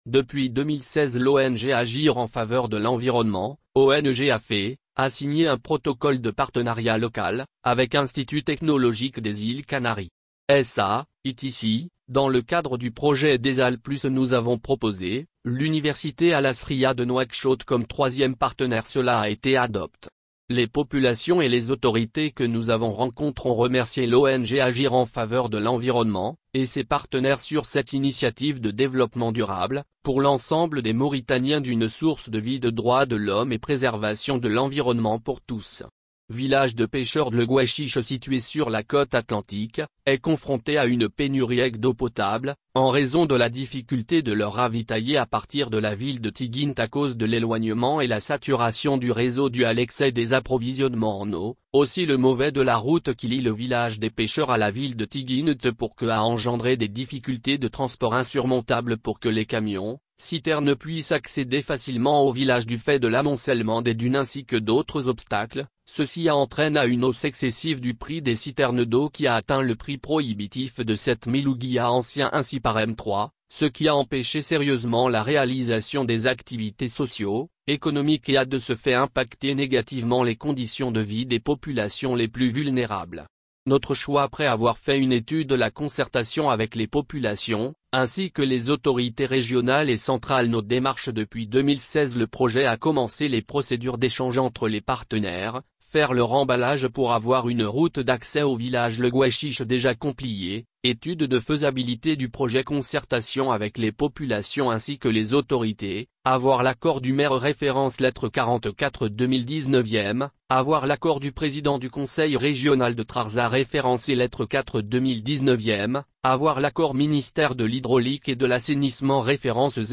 lieu d'unité de dessalement d'eau de mer eau potable village zone LEGWEICHICHE sur littoral Explication Technique aux universitaires